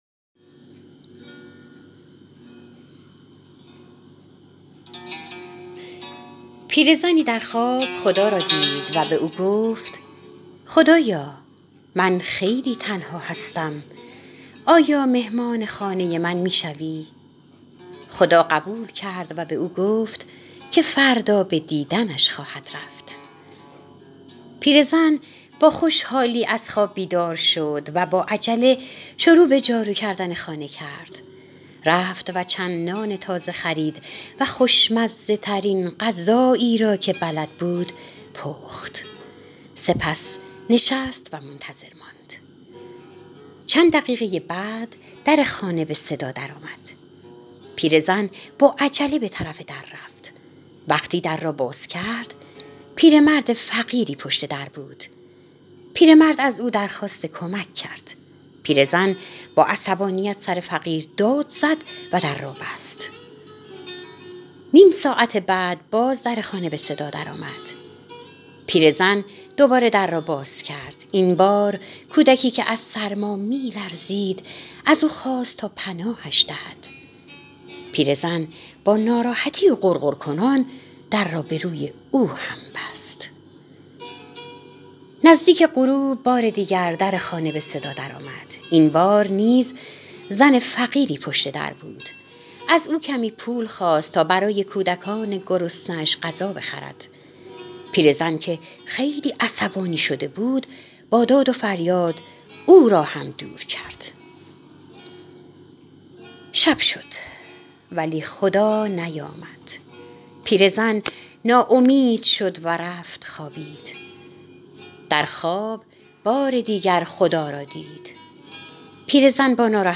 کتاب صوتی من منم!
داستانی-کوتاه-از-کتاب-من-منم؟.mp3